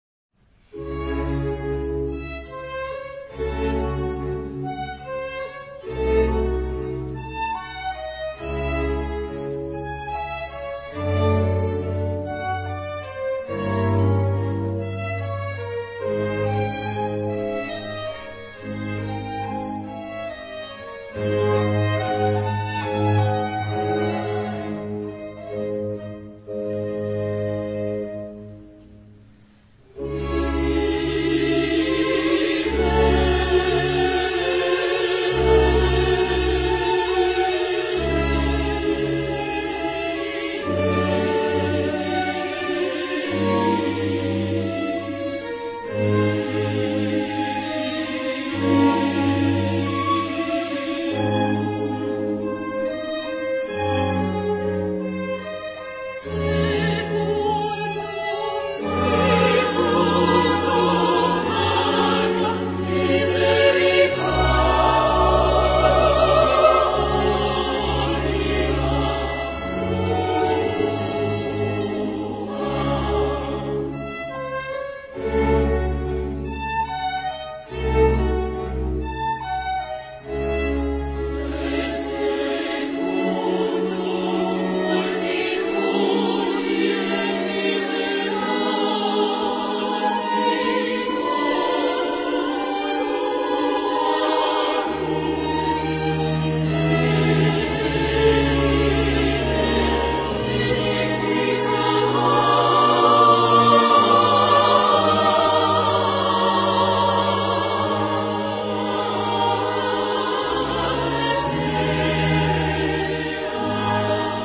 這是一份現場錄音，小型的合奏團，加上18人的小合唱團組成。
但是，現場的演出，非常動人。